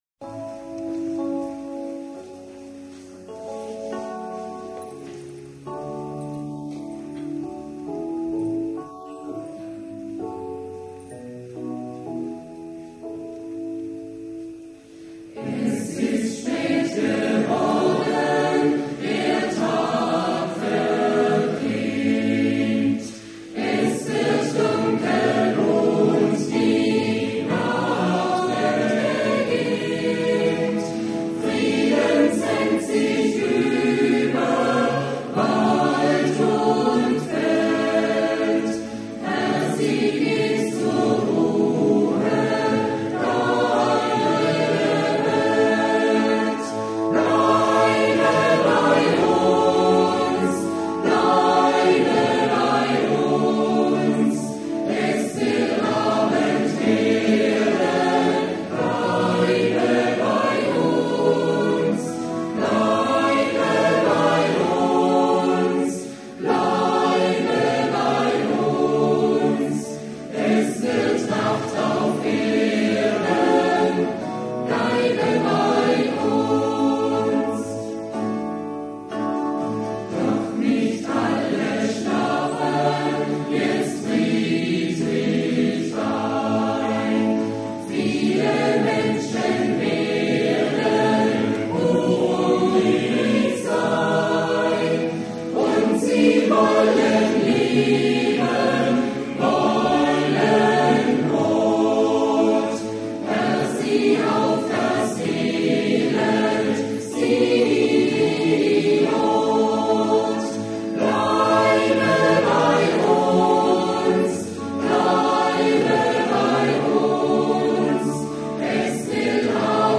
The music is taken of a concert by the choir in Aidlingen/ Germany.
Not so easy to play drums in a traditional church building with the acoustics there.
I only did the recording with the Soundfield Microphone, somewhere in the room
All songs, track after track below without any commentaries.